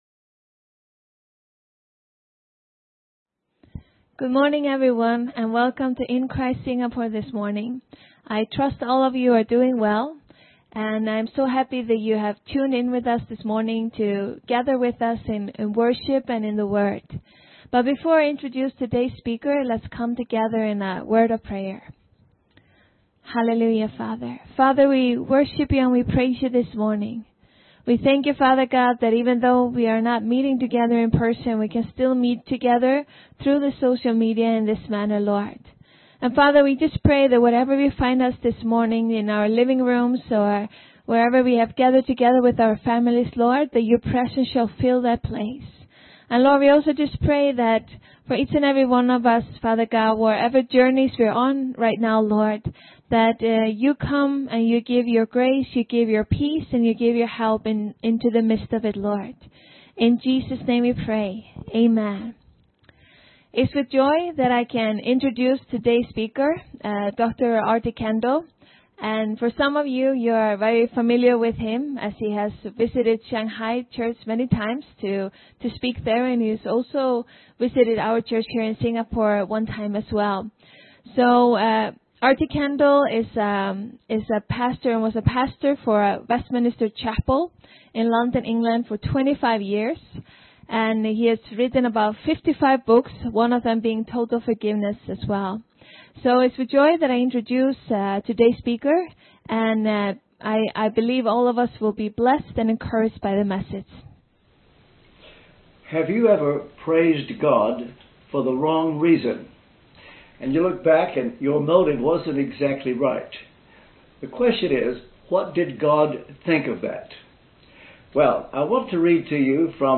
Palm Sunday Sermon by Dr RT Kendall, 5 Apr 2020, 10am service